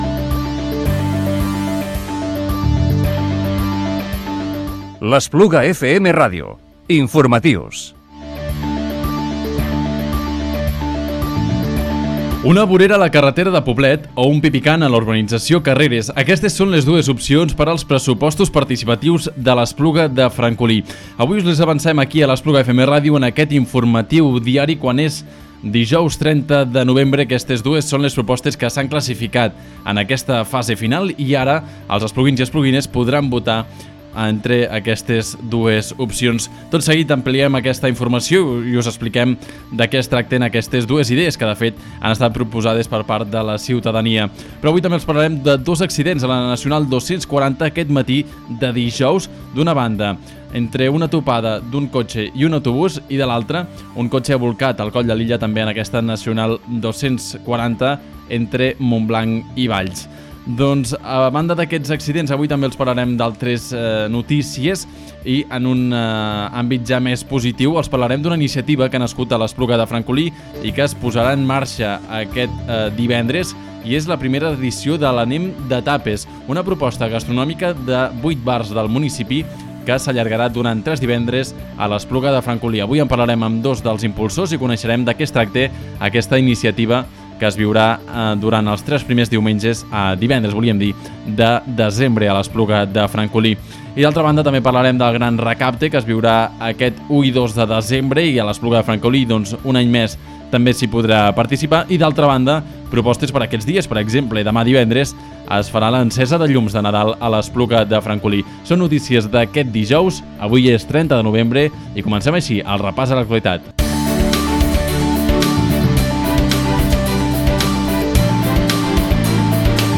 Informatiu Diari del dijous 30 de novembre del 2017